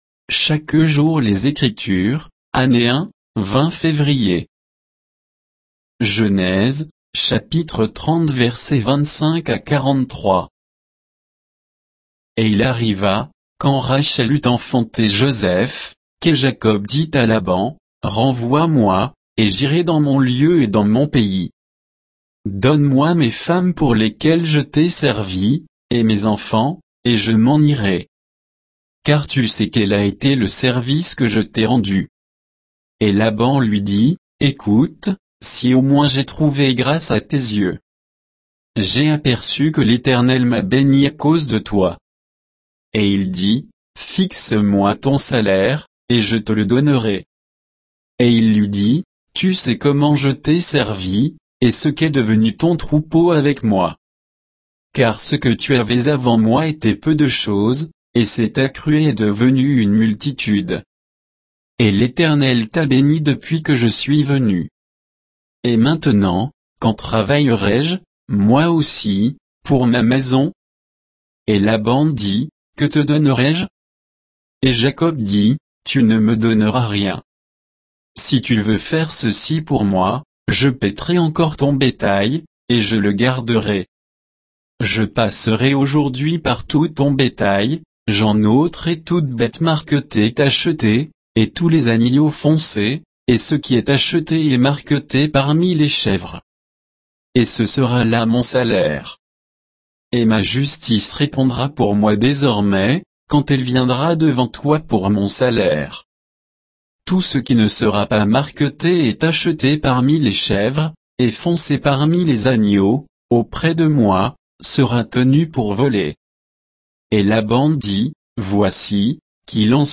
Méditation quoditienne de Chaque jour les Écritures sur Genèse 30, 25 à 43